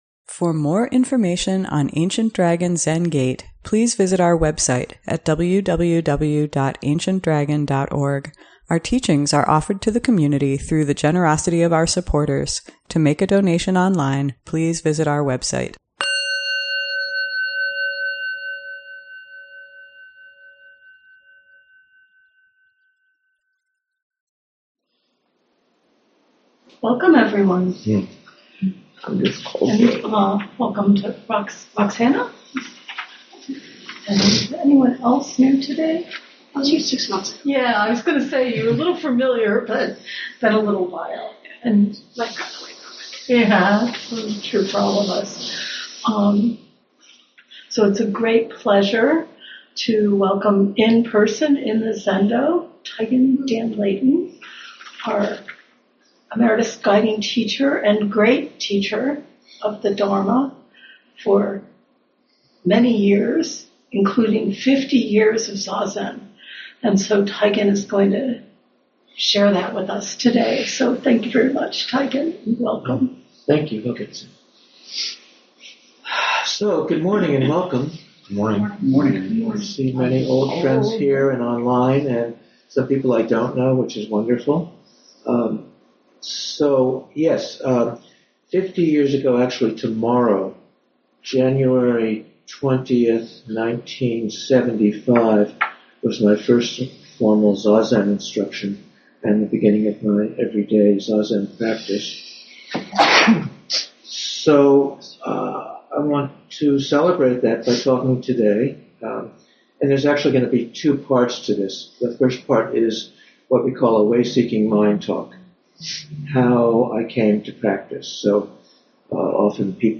Sunday Morning Dharma Talk